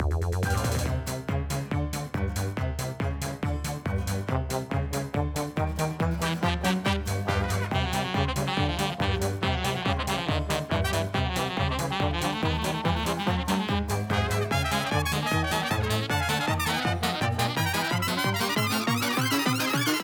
Ripped from the game